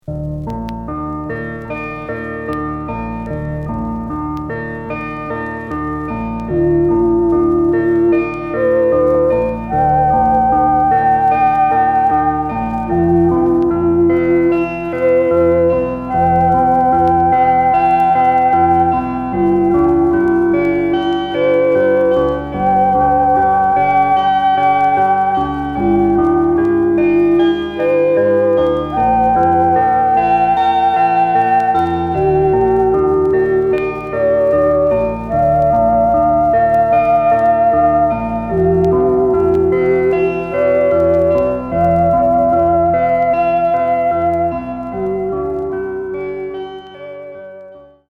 宅録 　電子音